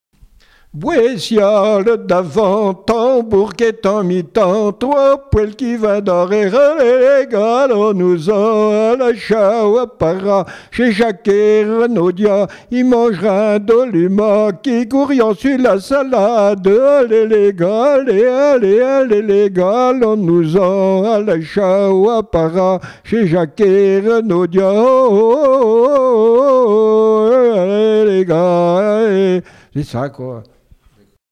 Appels de labour, tiaulements, dariolage, teurlodage, pibolage
couplets vocalisés
Pièce musicale inédite